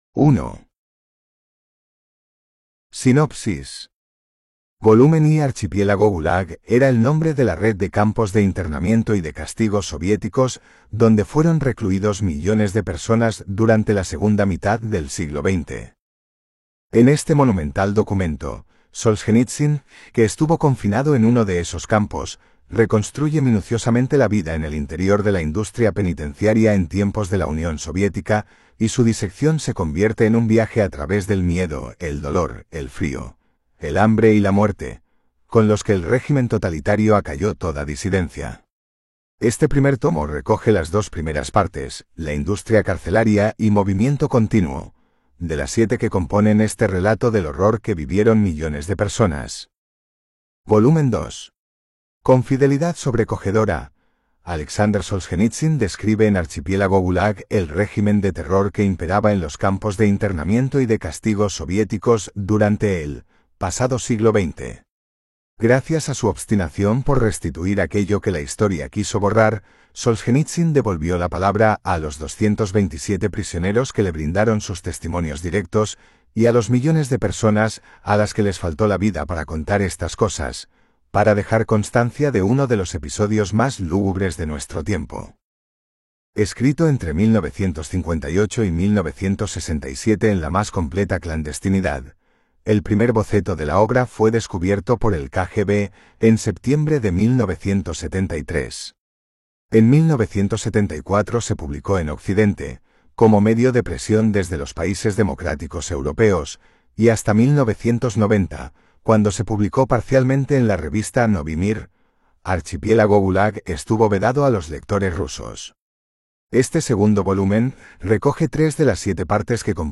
Escucha Archipiélago GULAG, el estremecedor testimonio de Aleksandr Solzhenitsyn sobre el sistema de campos soviéticos. Una obra clave de la literatura del siglo XX, ahora en formato audiolibro.
Archipielago-GULAG-Audiobook.m4a